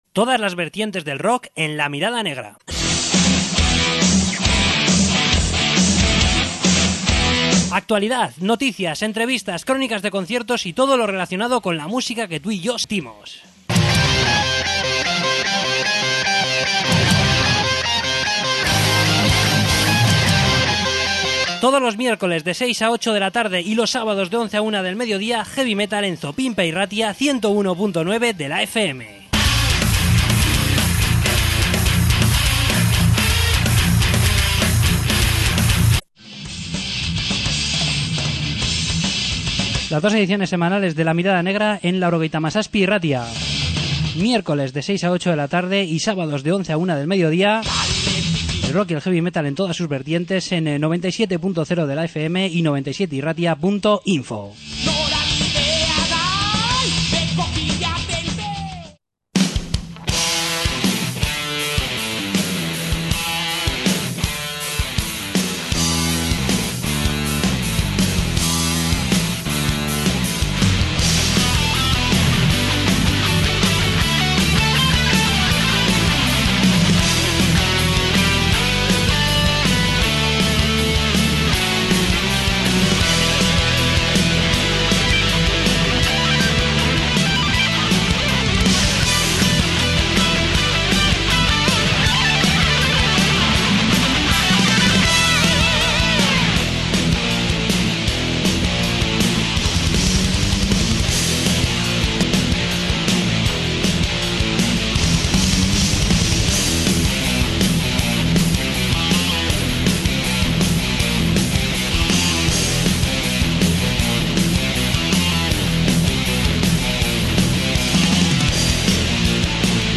Entrevista con Triple Zero Band